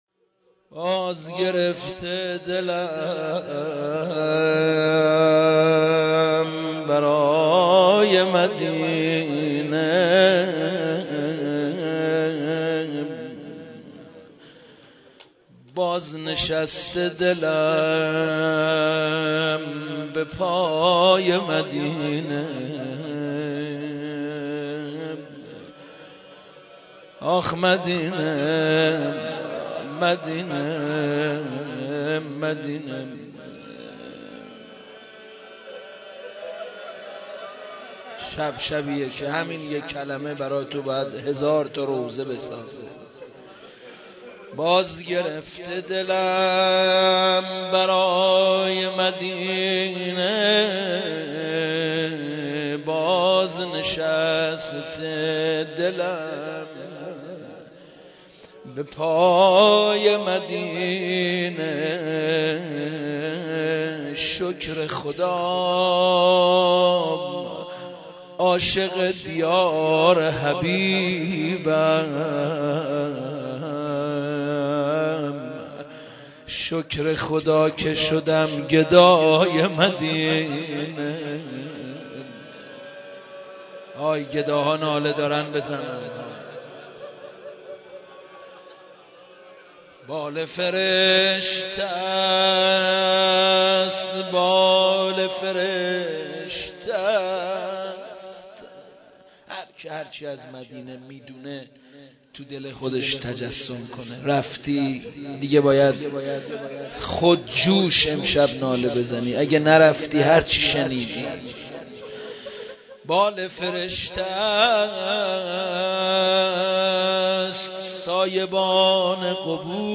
مرثيه سالروز تخریب قبور ائمه بقیع (ع) با سبک حسن خلج -( آنکه نام تو را نهاد بقیع ، رتبه ای داده ات بلند رفیع )